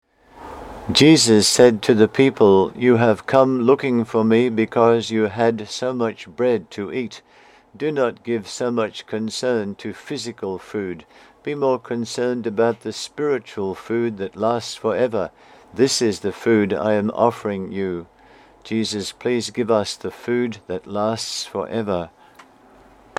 The Book Blog:   4 readings + recordings